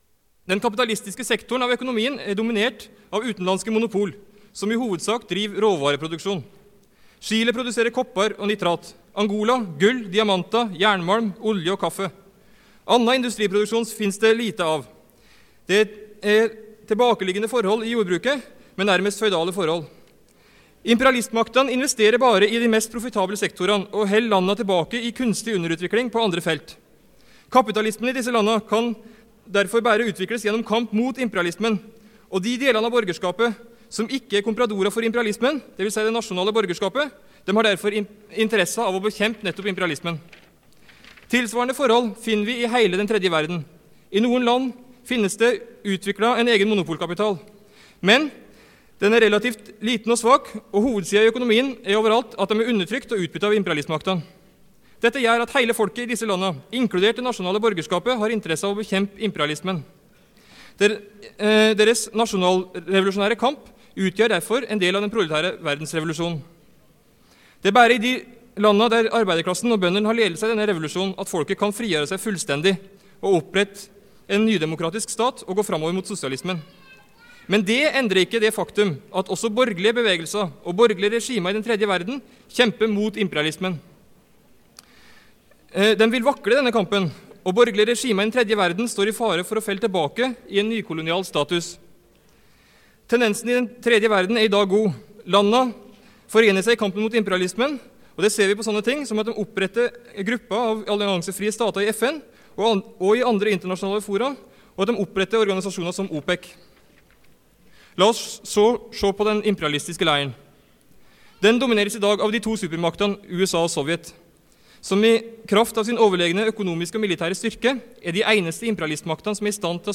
Det Norske Studentersamfund, Generalforsamling, 26.11.1977 (fil 1:15)